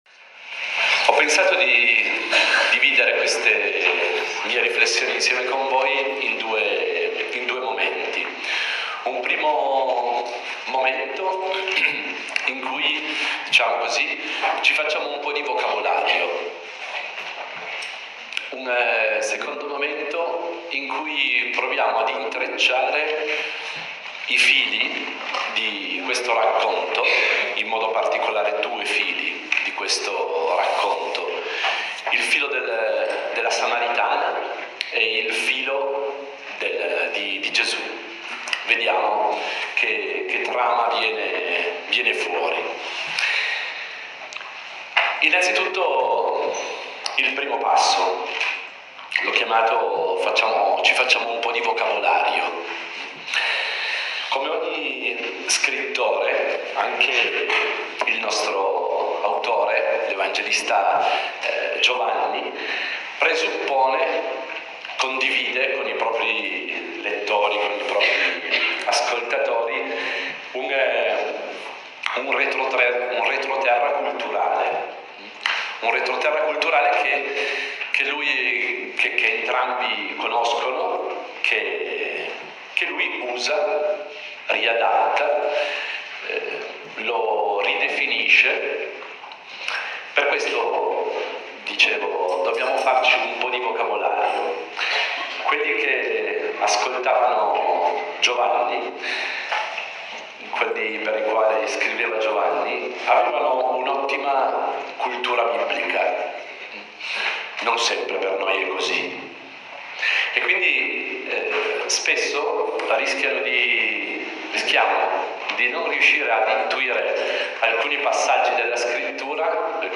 è stata proposta una meditazione sulla figura della Samaritana (Gv. capitolo 4)